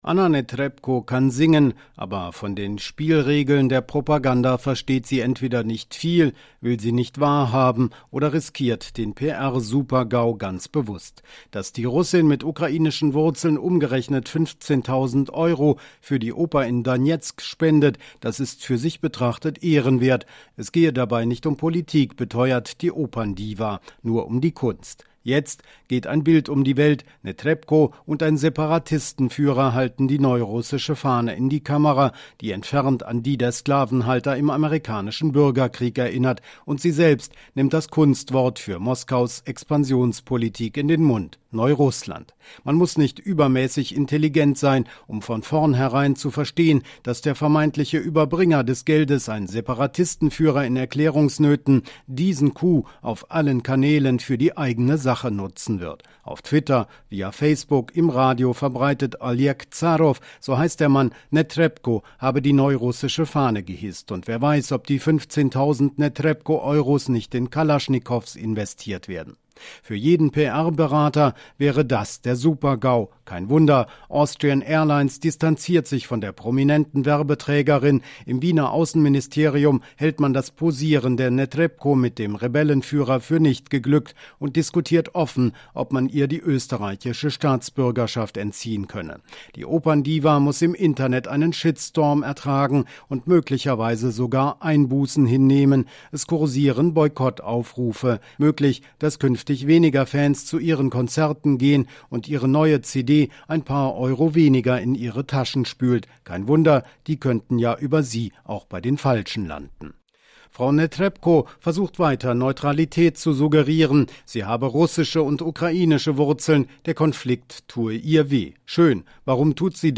Für-WDR-5-Kommentar-Netrebko-in-der-Propagandafalle.mp3